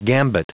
Prononciation du mot gambit en anglais (fichier audio)
Prononciation du mot : gambit